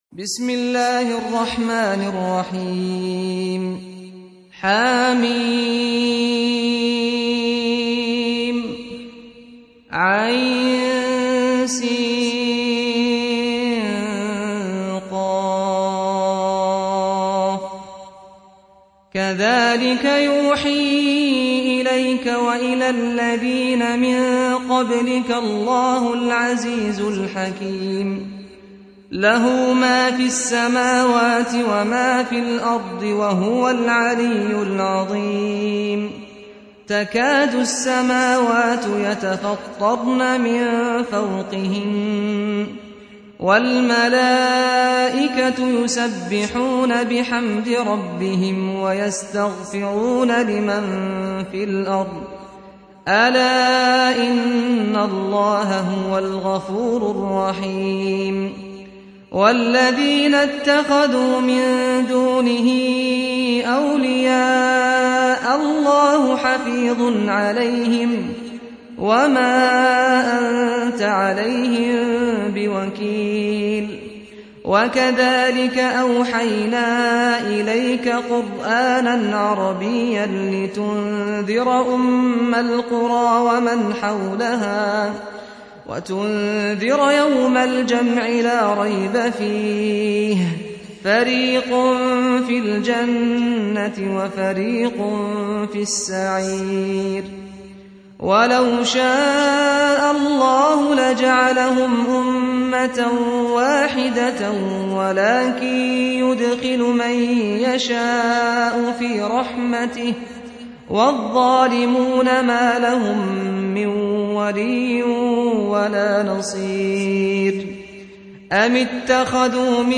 42. Surah Ash-Sh�ra سورة الشورى Audio Quran Tarteel Recitation
Surah Repeating تكرار السورة Download Surah حمّل السورة Reciting Murattalah Audio for 42.